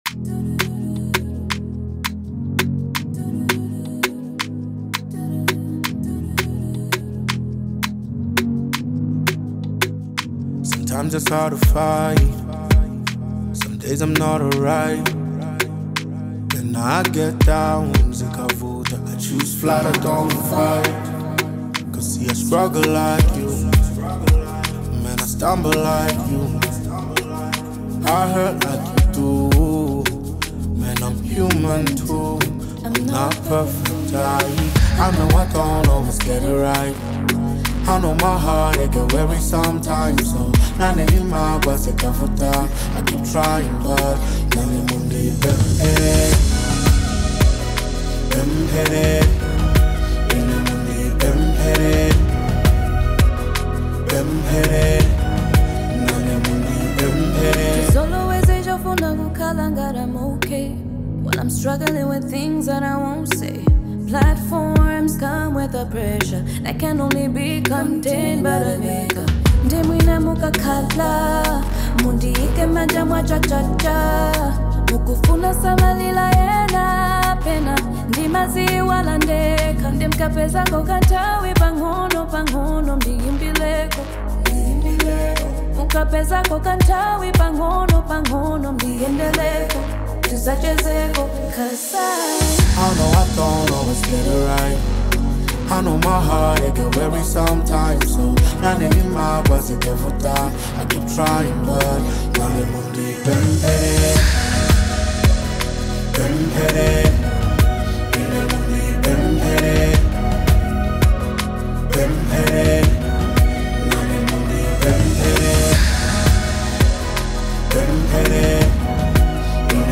Genre : Gospel
With powerful harmonies and uplifting production